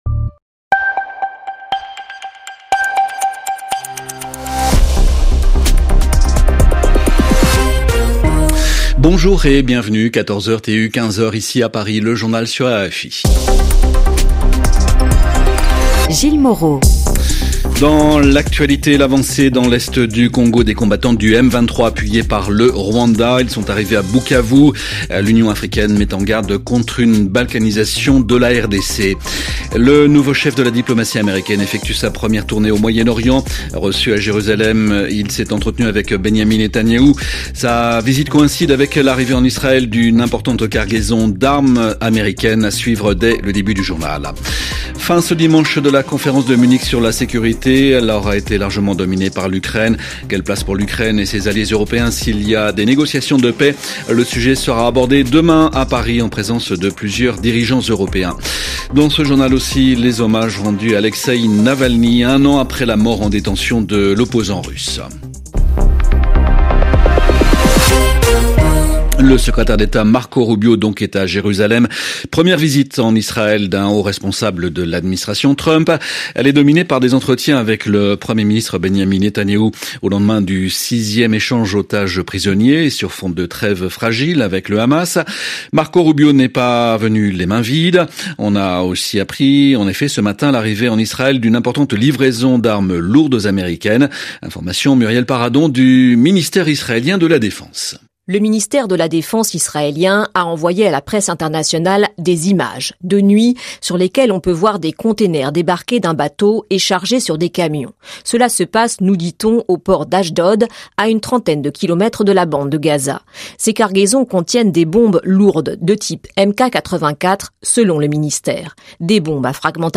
Retrouvez tous les journaux diffusés sur l’antenne de RFI pour suivre l’actualité internationale, africaine, politique, culturelle ou sportive.